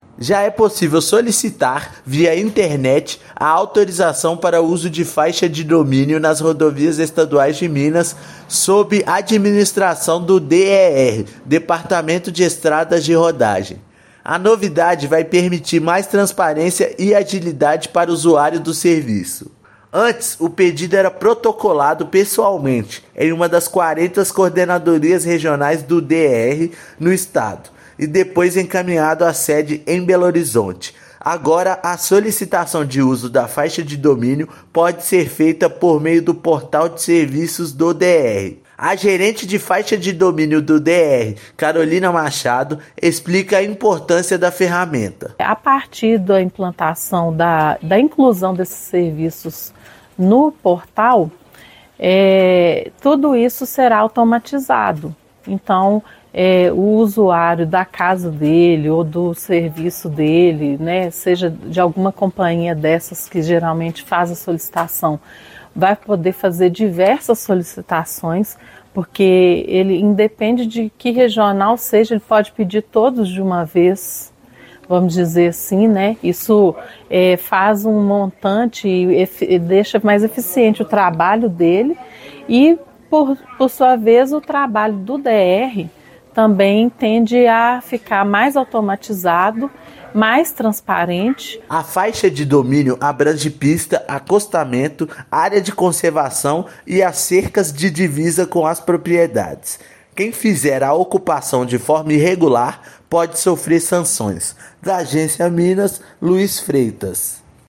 Disponível a partir dessa quinta-feira (20/2) no site do DER-MG, serviço vai dar mais agilidade e transparência às solicitações. Ouça matéria de rádio.